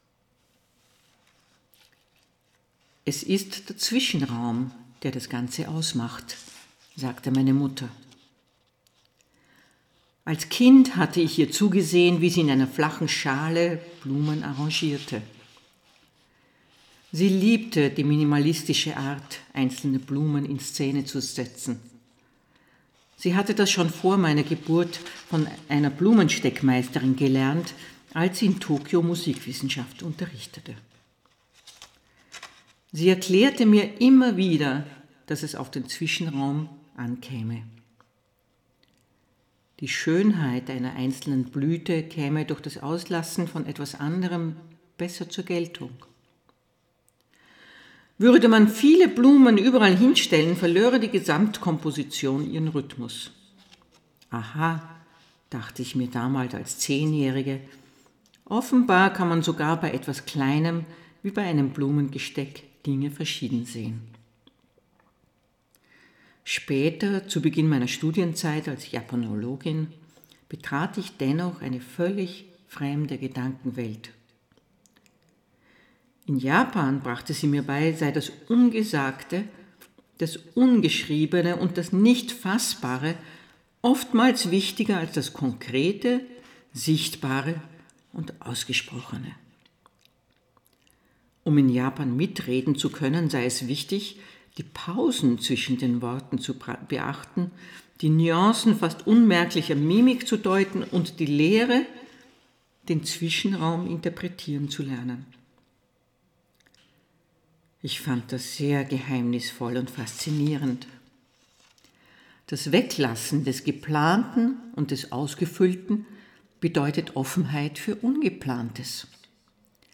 Es ist keine Profi-Aufnahme, ich lese selbst.